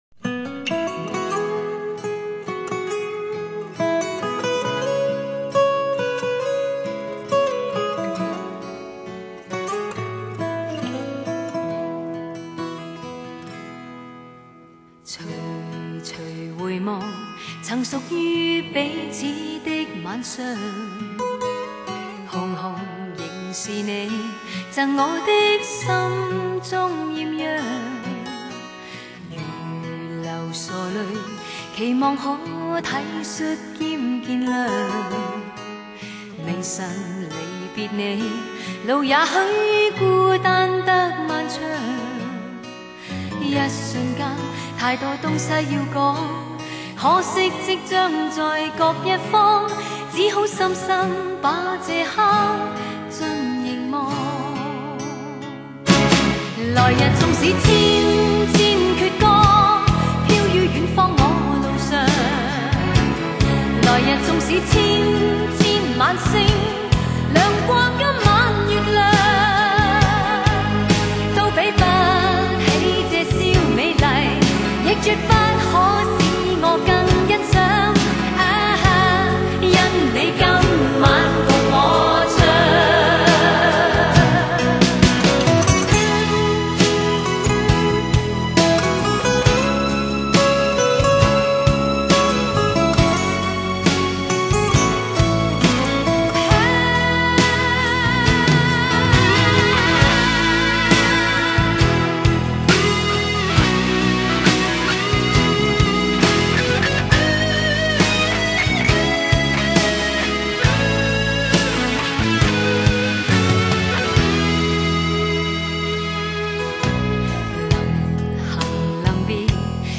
音乐类型: 试音碟